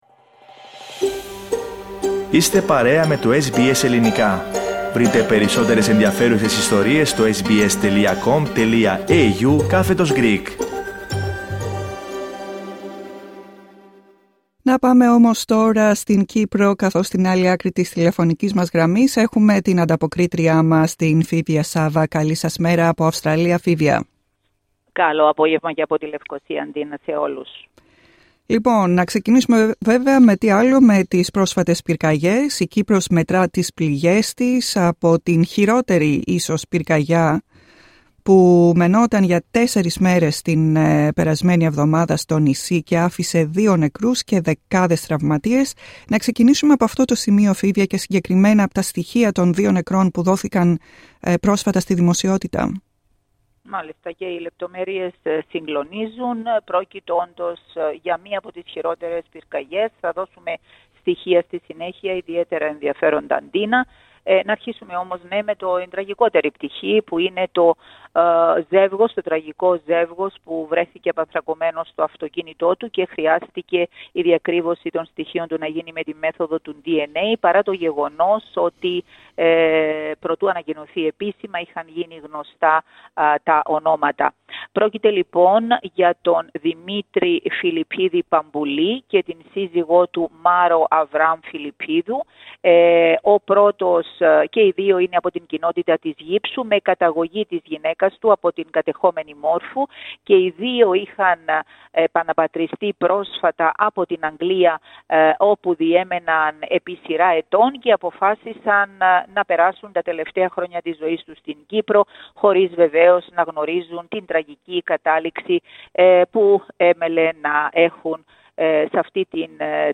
Περισσότερα ακούμε στην ανταπόκριση